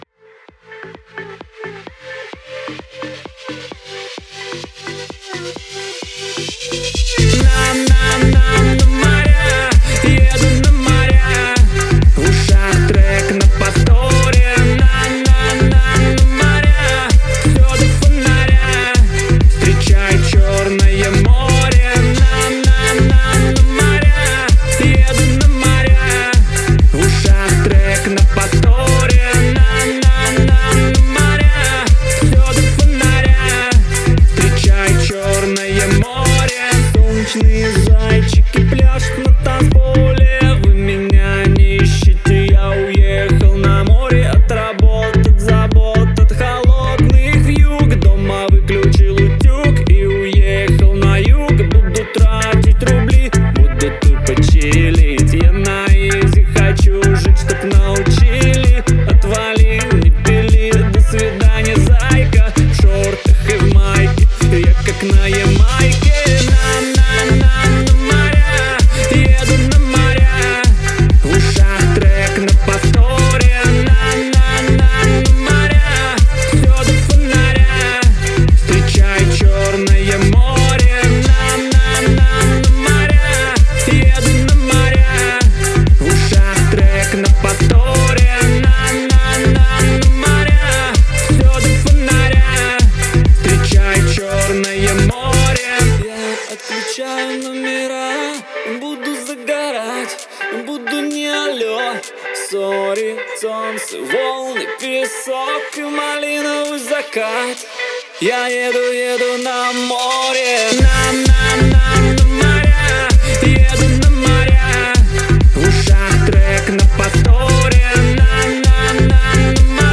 который сочетает в себе элементы поп и электронной музыки.